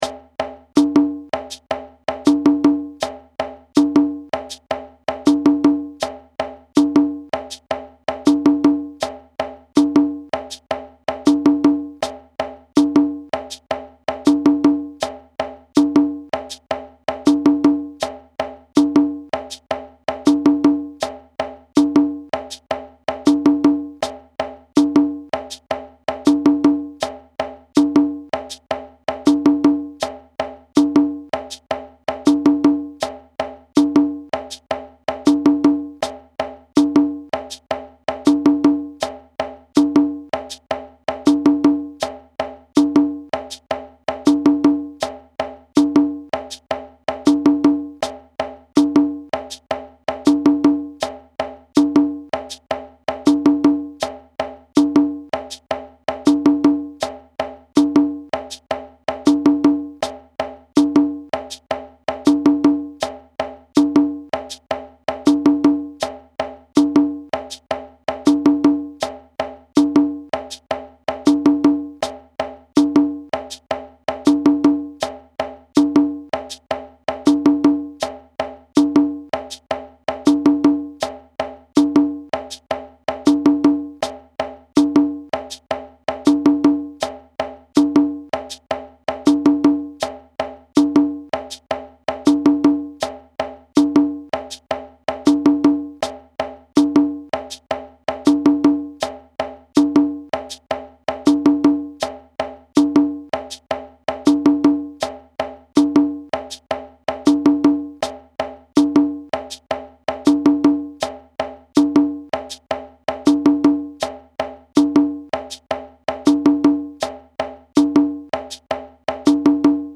A drum circle favorite, this electric rhythm incorporates West African and Brazilian rhythm concepts (in 4/4 time).
high part audio (with shekeré)
West-African-Samba-high-hh.mp3